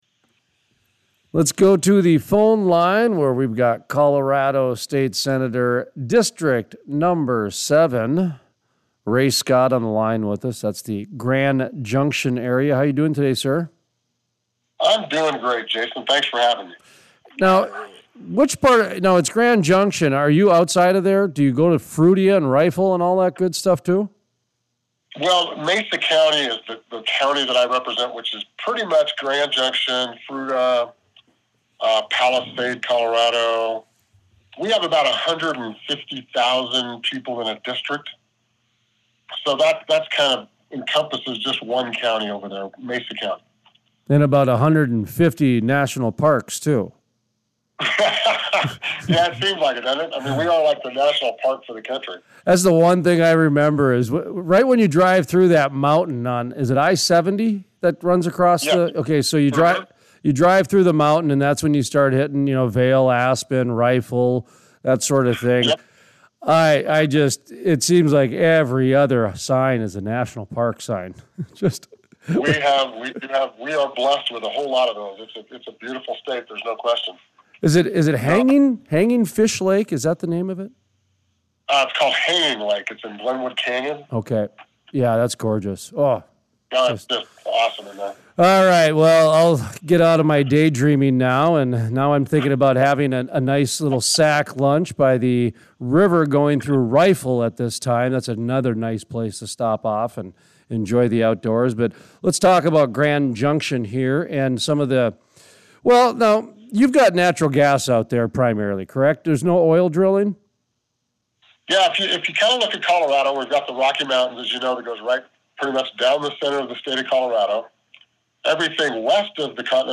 Colorado State Senator Ray Scott gives an update on the 2022 Colorado Legislative Session.
Full Length Interviews